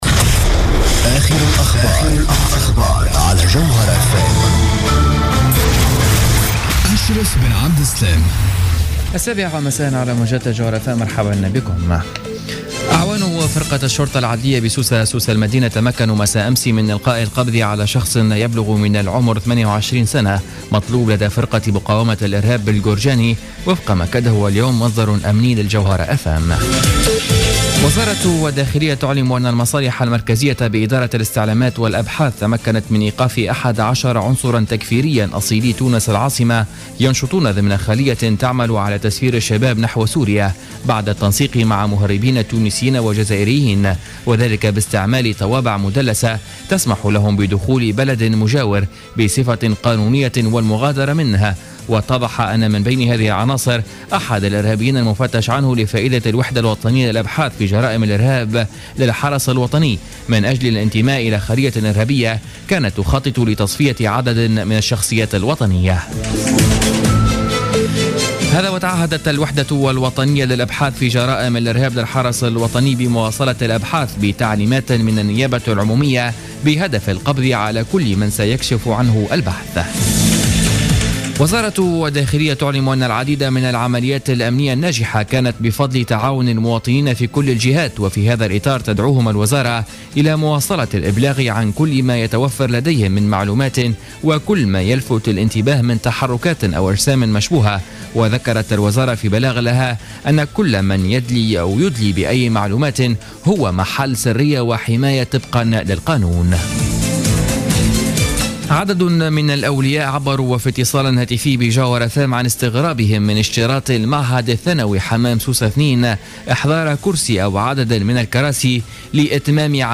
نشرة أخبار السابعة مساء ليوم الثلاثاء 08 سبتمبر 2015